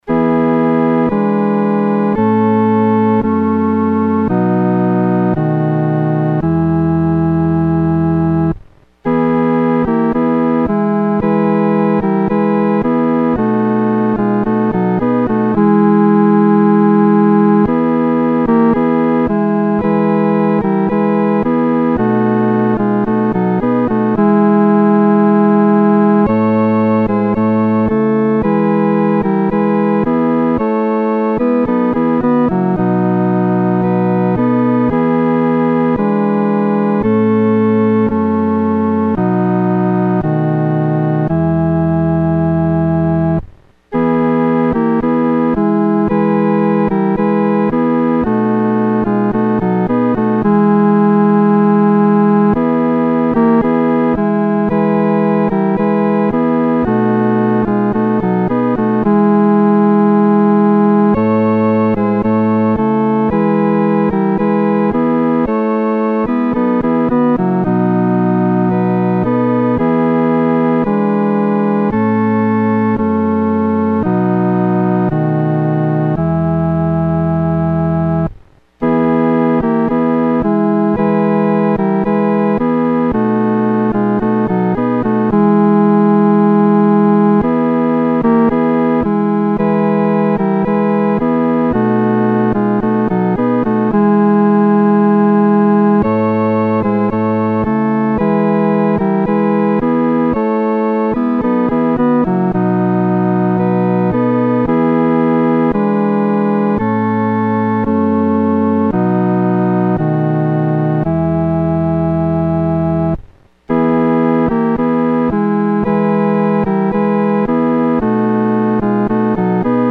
独奏（第三声）
赞父奇爱-独奏（第三声）.mp3